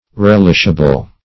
Meaning of relishable. relishable synonyms, pronunciation, spelling and more from Free Dictionary.
Search Result for " relishable" : The Collaborative International Dictionary of English v.0.48: Relishable \Rel"ish*a*ble\ (-?-b'l), a. Capable of being relished; agreeable to the taste; gratifying.